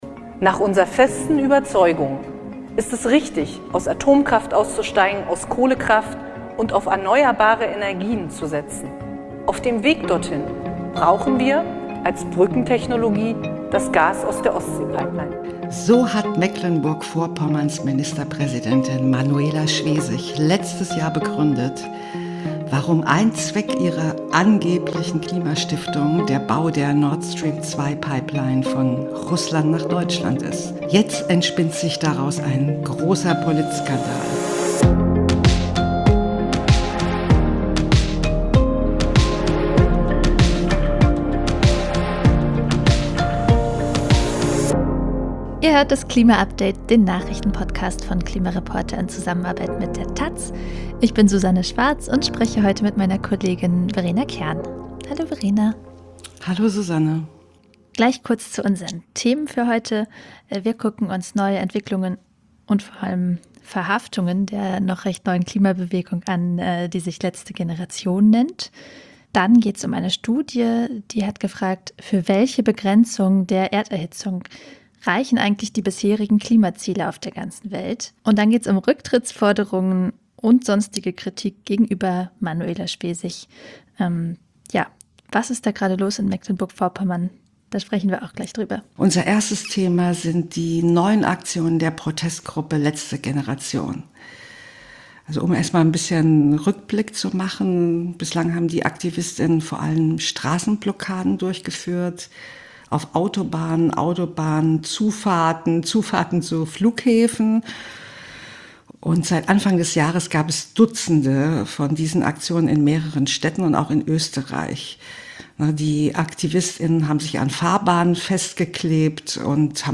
Im klima update° besprechen Journalistinnen vom Online-Magazin klimareporter° und von der Tageszeitung taz jeden Freitag die wichtigsten Klima-Nachrichten der Woche.